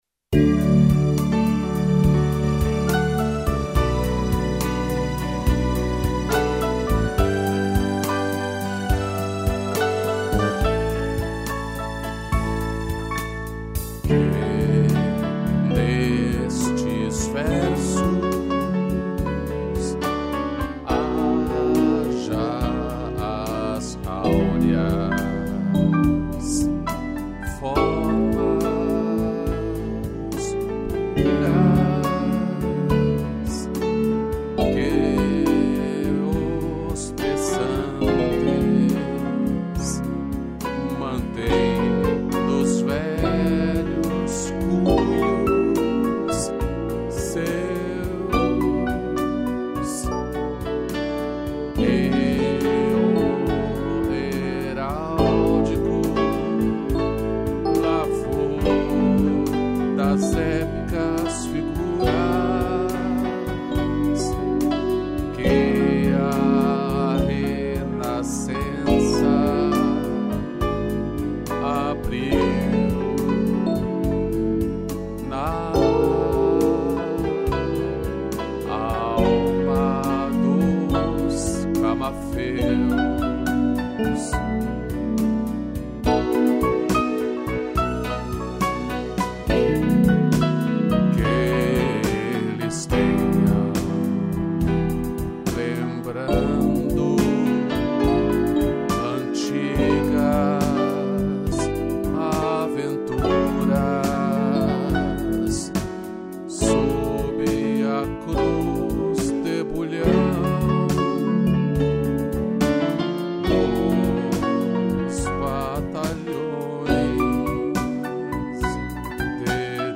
piano, sax e strings